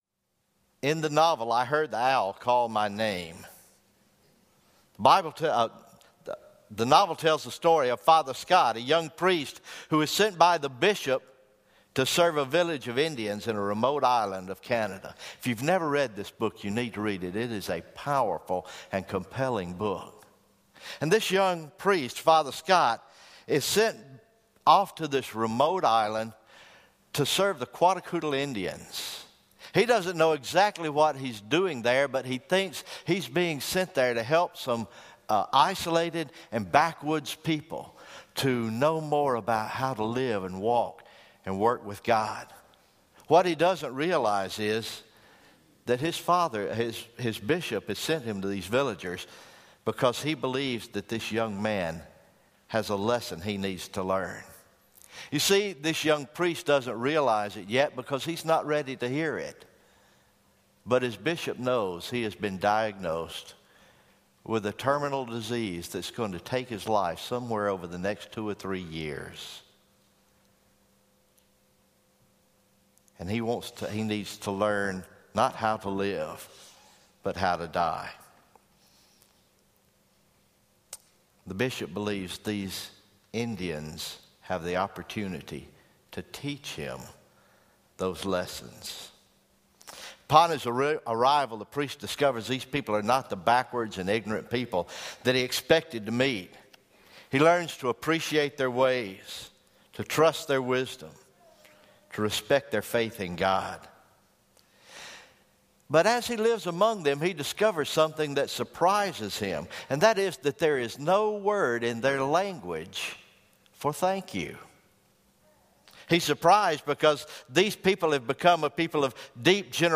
November 20, 2016 Morning Worship